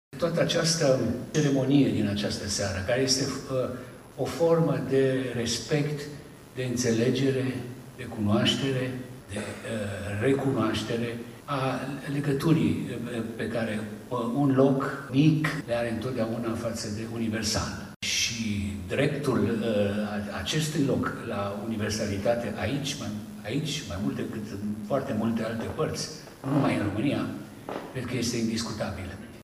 La Teatrul Mihai Eminescu din Botoșani a avut loc aseară Gala Culturii Naționale.
Premiul Național pentru promovarea operei eminesciene și a culturii române a fost decernat scriitorului Emil Hurezeanu, Ministru al Afacerilor Externe.
15-ian-Emil-Hurezeanu-site.mp3